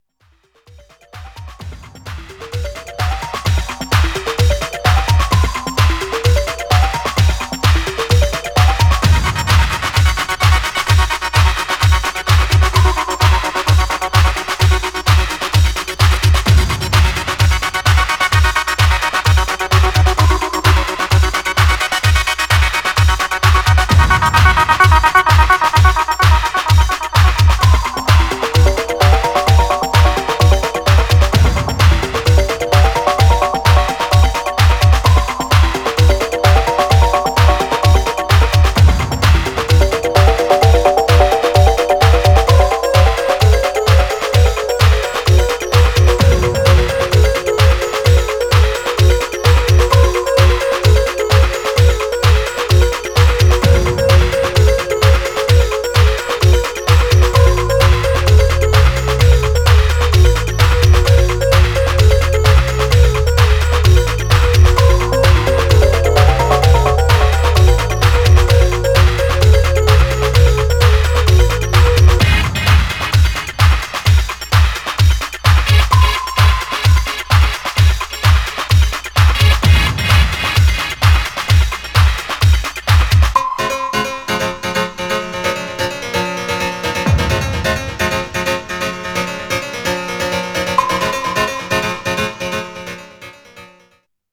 Styl: House Vyd�no